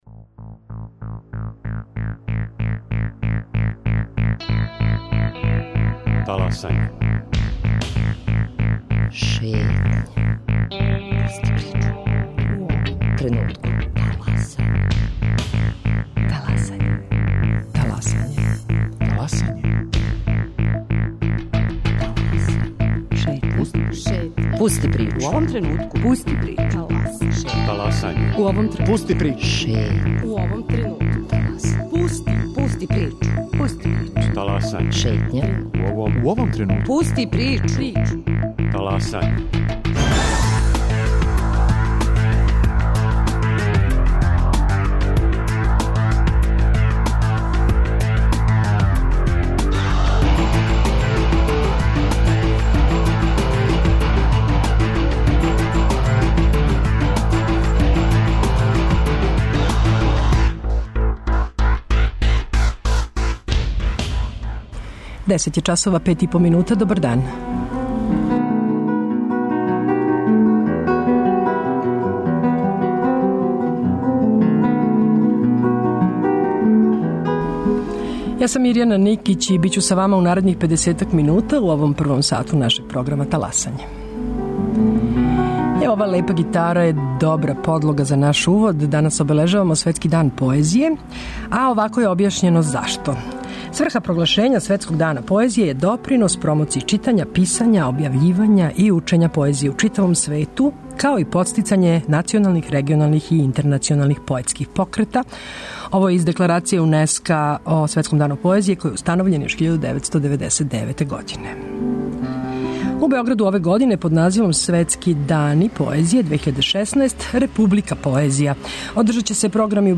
Тим поводом ће и новинари Радио Београда, у Шетњи, казивати своје омиљене стихове.